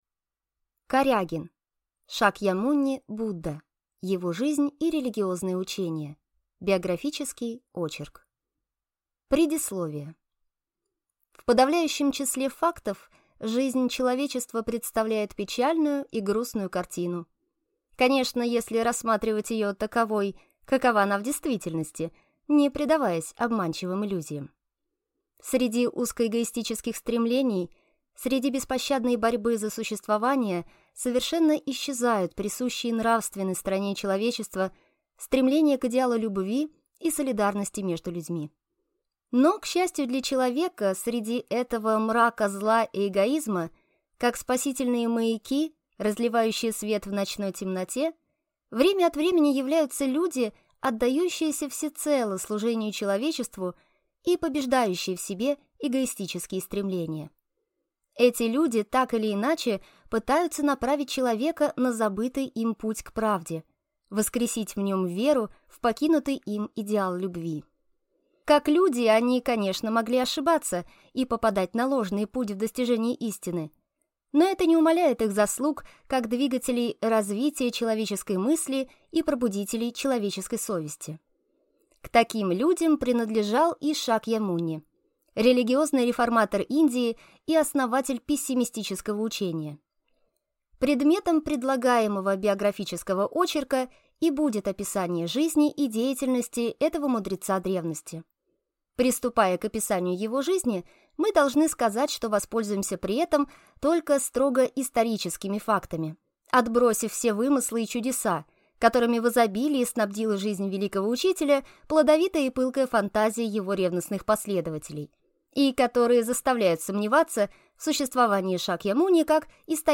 Аудиокнига Шакьямуни (Будда). Его жизнь и религиозное учение | Библиотека аудиокниг